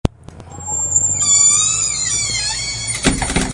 摔门声
描述：门吱吱作响。
标签： 吱吱 斗R 踩住 门砰
声道立体声